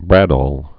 (brădôl)